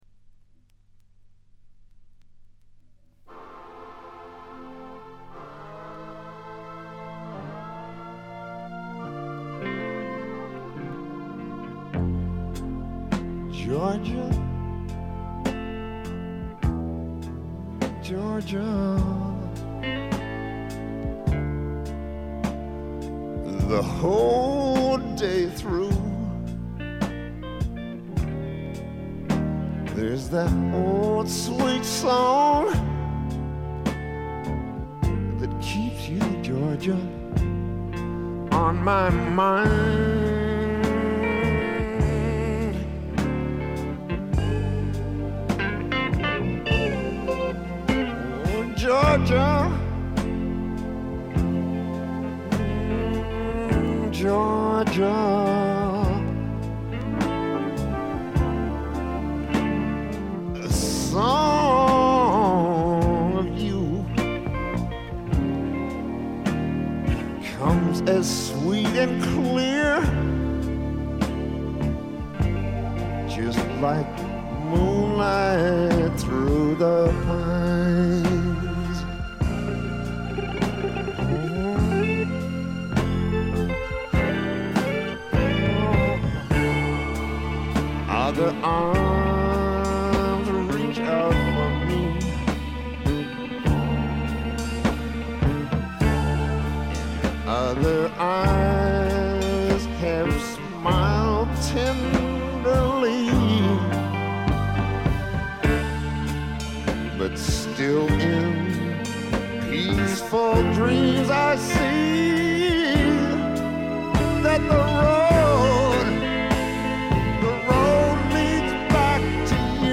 部分試聴ですが、わずかなノイズ感のみ。
試聴曲は現品からの取り込み音源です。